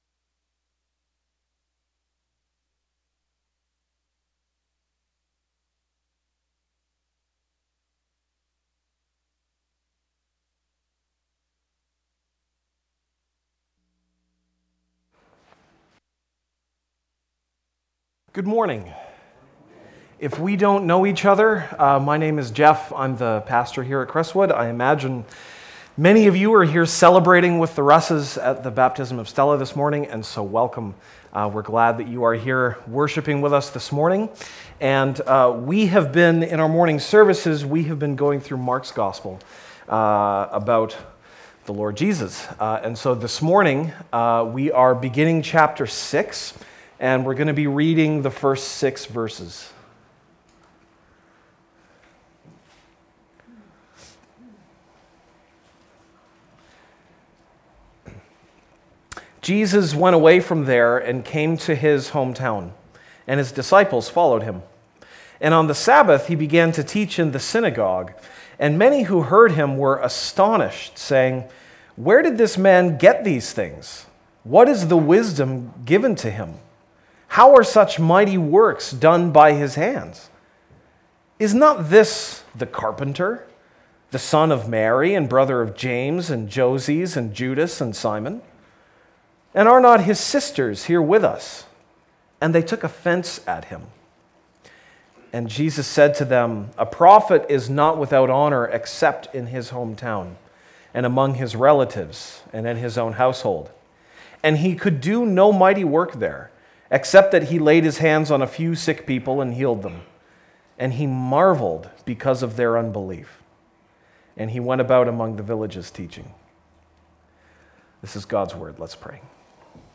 February 7, 2016 (Sunday Morning)